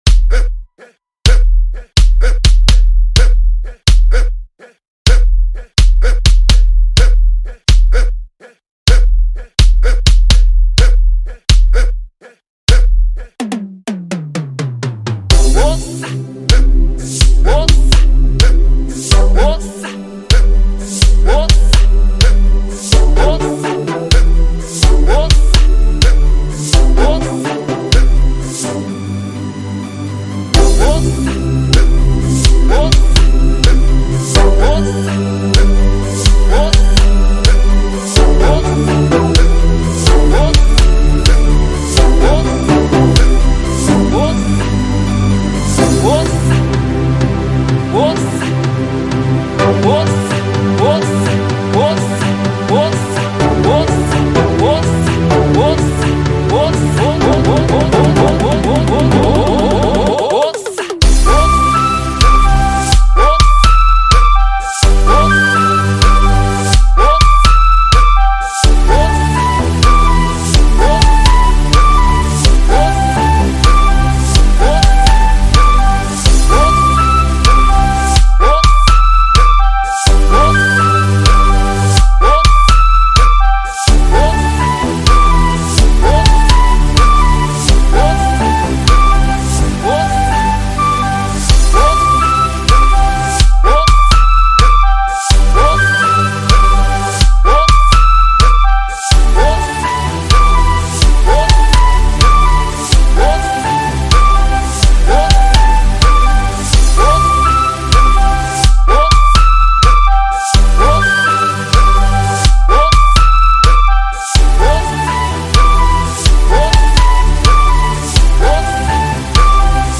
gqom
captures the freshest sounds